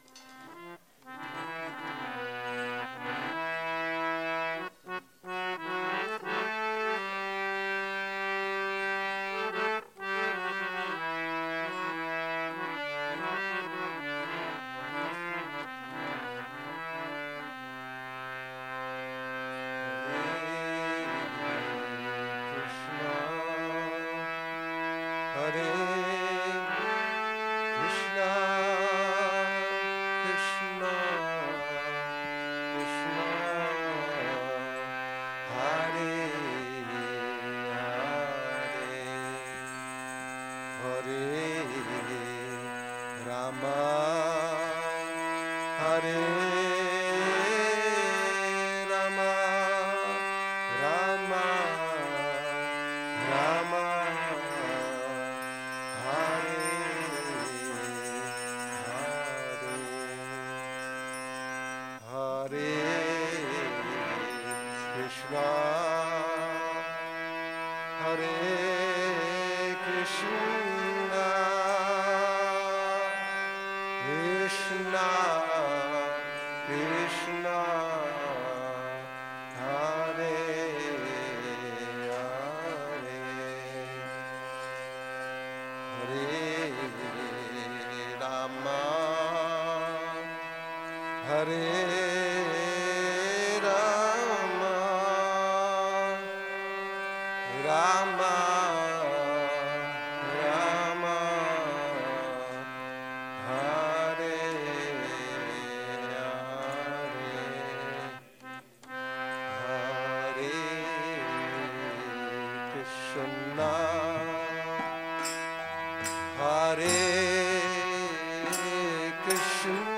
Kírtan – Šrí Šrí Nitái Navadvípačandra mandir